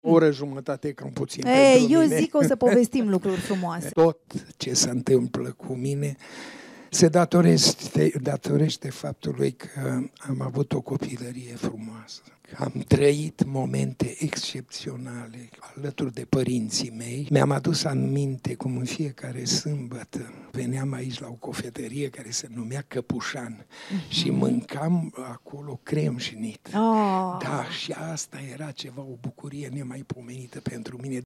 Interviu record difuzat de Europa FM – Florin Piersic a vorbit două ore La RADIO, cu Andreea Esca
Cel mai lung interviu live difuzat vreodată de Europa FM a durat două ore. Maestrul Florin Piersic a vorbit cu Andreea Esca în emisiunea La Radio, despre copilăria sa, despre cine și ce l-a ajutat să devină actorul de astăzi. La 81 de ani, Andreea Esca a reușit să îl emoționeze pe marele actor, încât a plâns în direct.